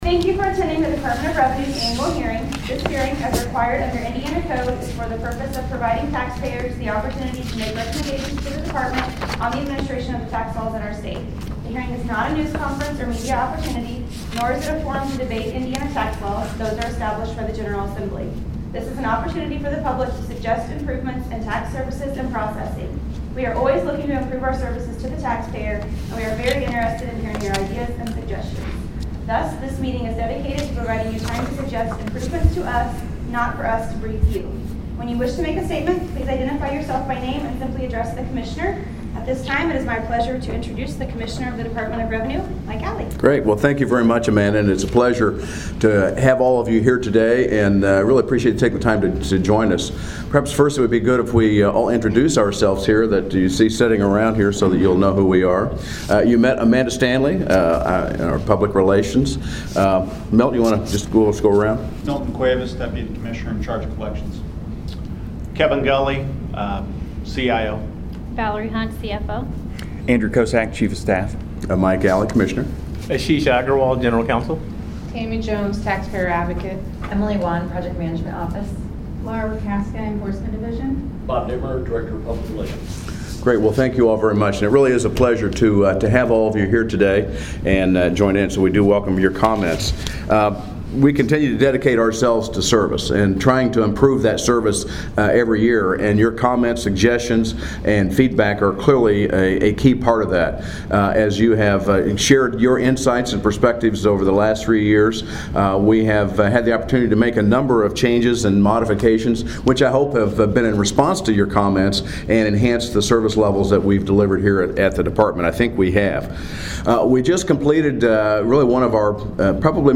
Annual Public Hearings
2015-annual-hearing.MP3